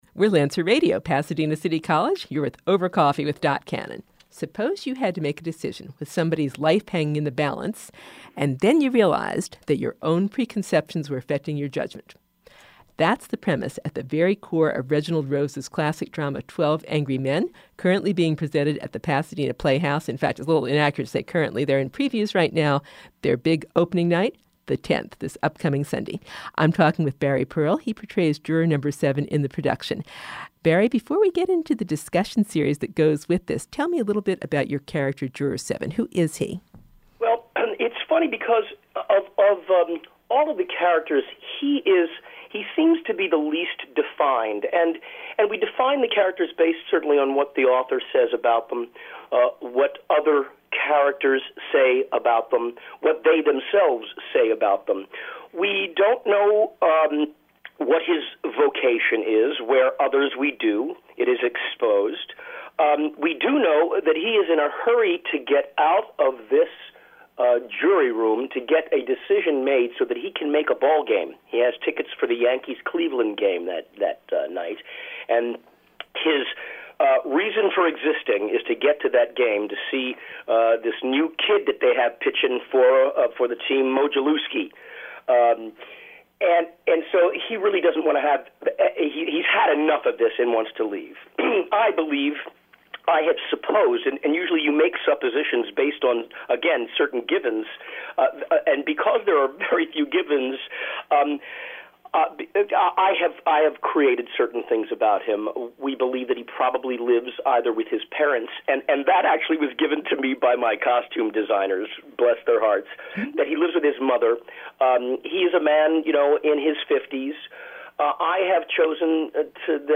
Barry Pearl Interview, Part 2